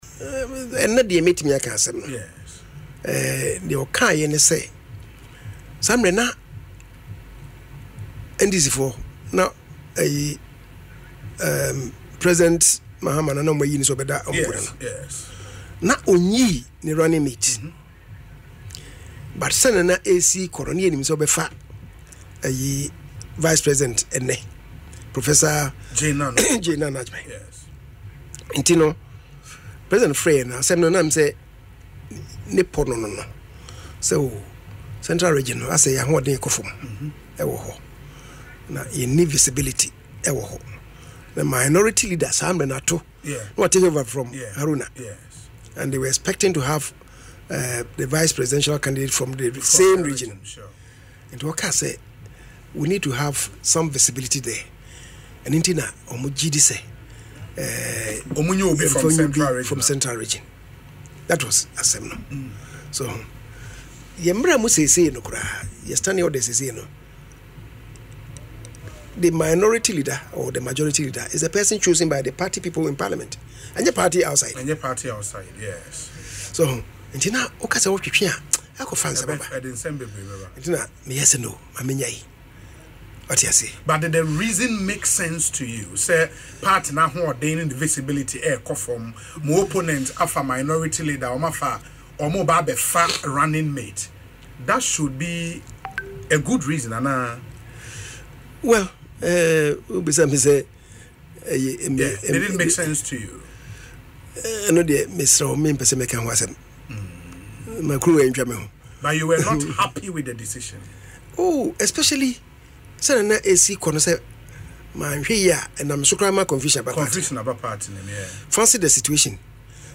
In an interview on Asempa FM’s Ekosii Sen, Kyei-Mensah-Bonsu revealed that the NPP wanted a leader from the Central region, following the appointment of Dr. Cassiel Ato Forson from the same region as the Minority Leader.